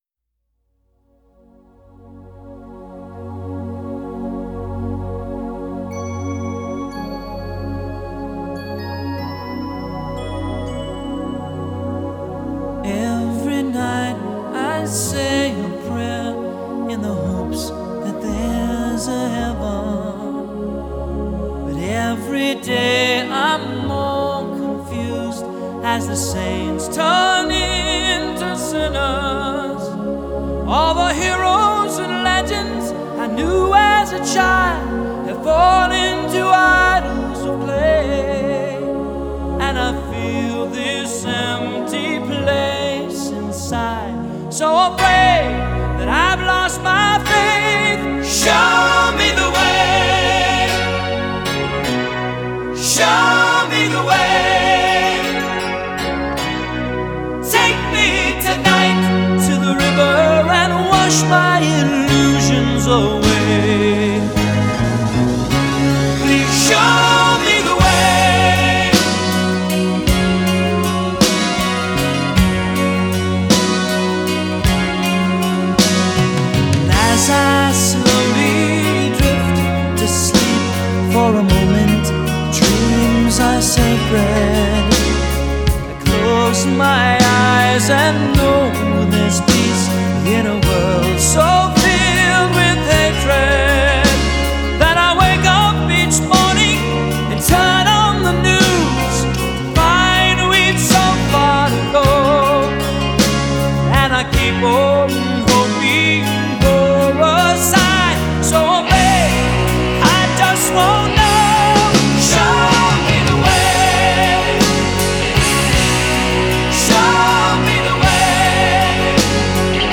Genre: Rock, Pop
Style: Classic Rock